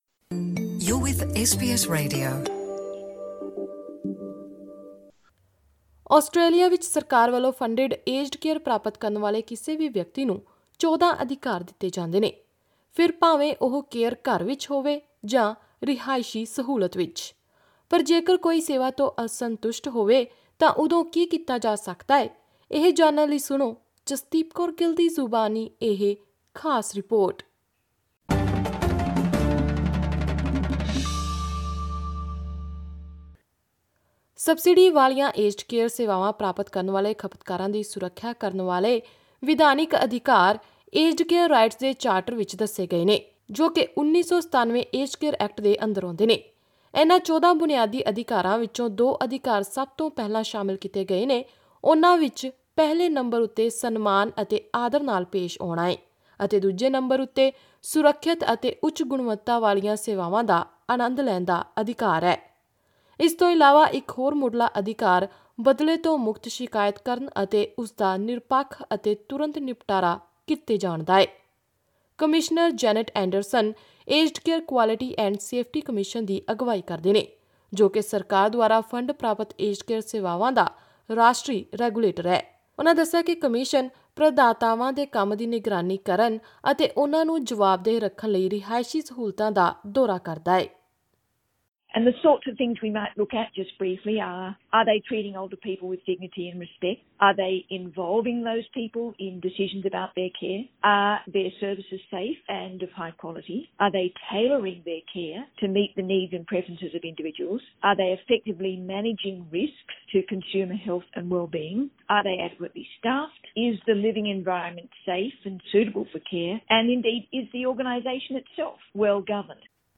ਆਸਟ੍ਰੇਲੀਆ ਵਿੱਚ ਸਰਕਾਰ ਵਲੋਂ ਏਜਡ ਕੇਅਰ ਵਿਚਲੇ ਕਿਸੇ ਵੀ ਵਿਅਕਤੀ ਨੂੰ 14 ਅਧਿਕਾਰ ਦਿੱਤੇ ਗਏ ਹਨ, ਫਿਰ ਭਾਵੇਂ ਉਹ 'ਕੇਅਰ' ਘਰ ਵਿੱਚ ਹੋਵੇ ਜਾਂ ਰਿਹਾਇਸ਼ੀ ਸਹੂਲਤ ਵਿੱਚ। ਪਰ ਜੇਕਰ ਕੋਈ ਦਿੱਤੀ ਜਾਂਦੀਆਂ ਸੇਵਾਵਾਂ ਤੋਂ ਅਸਤੁੰਸ਼ਟ ਹੋਵੇ ਤਾਂ ਉਦੋਂ ਕੀ ਕੀਤਾ ਜਾ ਸਕਦਾ ਹੈ, ਇਹ ਜਾਨਣ ਲਈ ਸੁਣੋ ਇਹ ਖ਼ਾਸ ਰਿਪੋਰਟ।